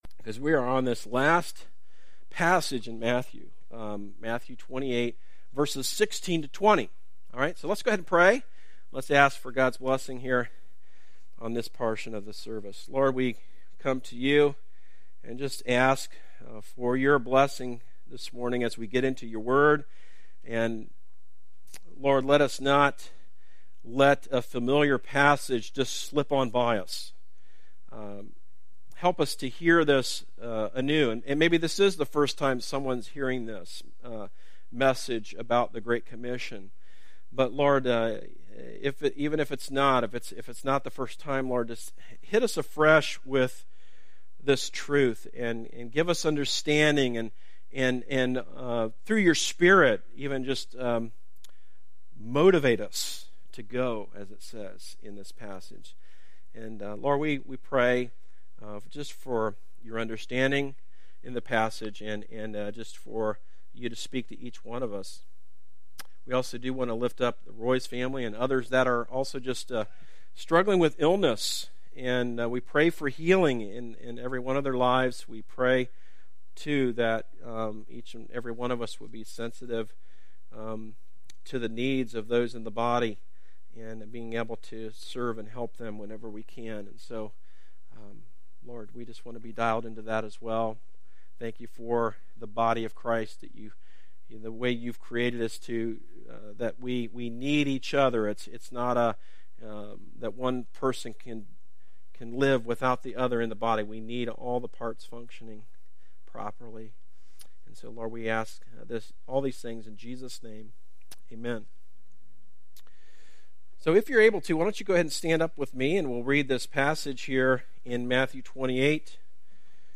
Sermons - Darby Creek Church - Galloway, OH